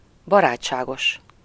Ääntäminen
France: IPA: /ɛ.mabl/